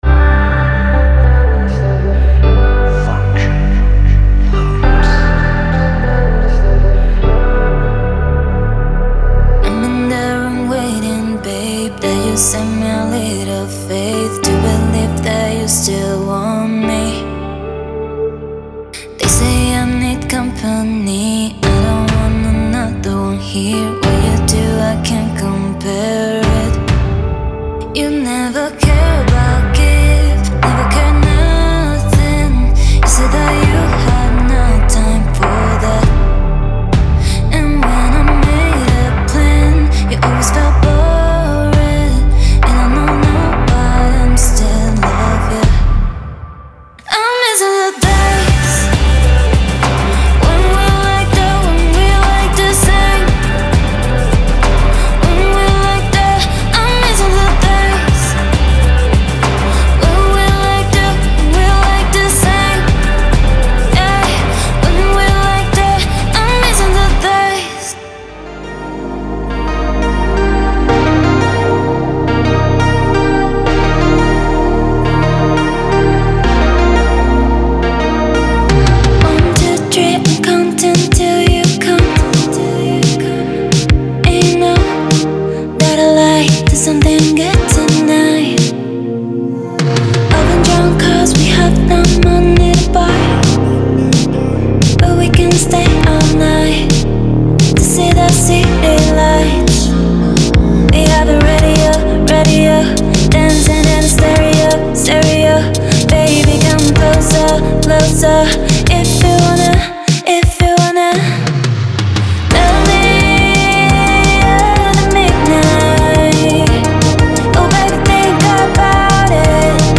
Genre:Pop
すべてのボーカルアカペラはDry/Wetバージョンで提供されます。
デモサウンドはコチラ↓
33 Drum Loops (full, kick, snare, perc, tops etc)
34 Instrument Loops (guitar, pad, piano, synth, lead etc)